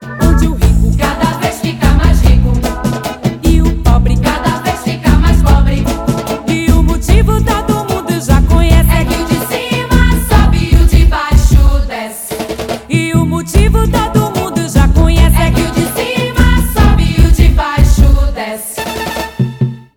Trecho da música